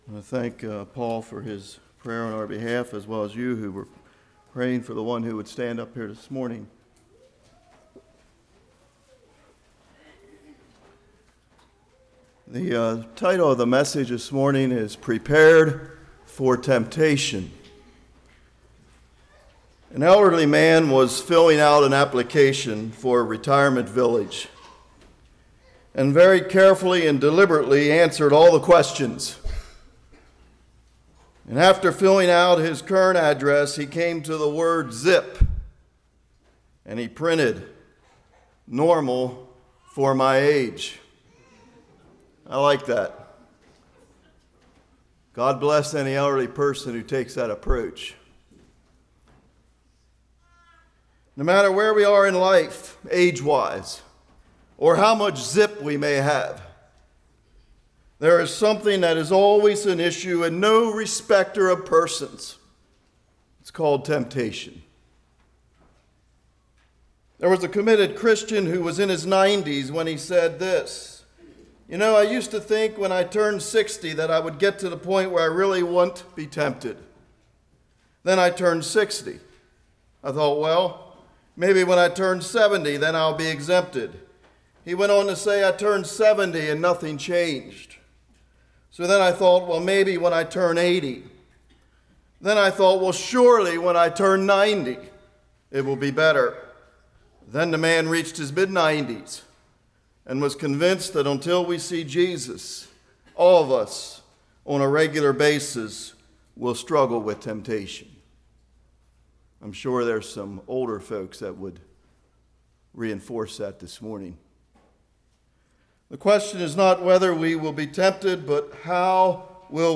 Matthew 4:1-11 Service Type: Morning Commit to what won’t trade Commit to Truth Be aware when vunerable « Wisdom or Folly What is Truth?